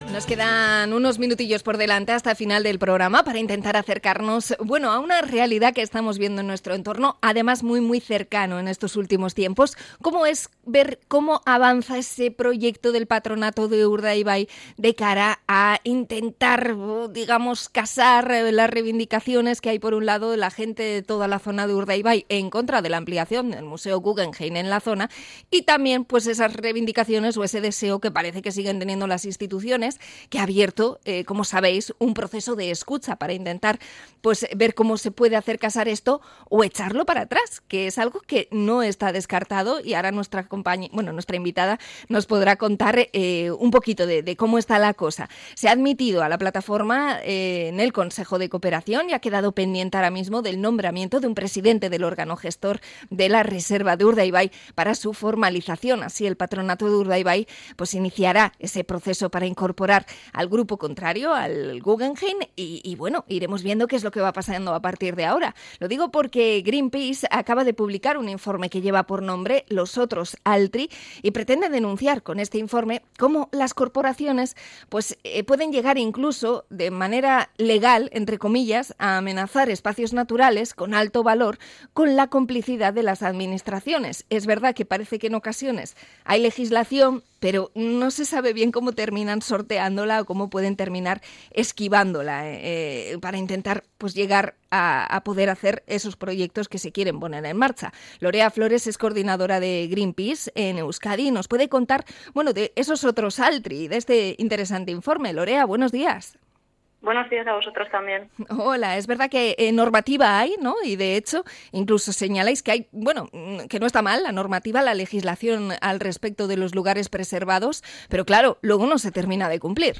Entrevista a Greenpeace por su informe Los Otros Altri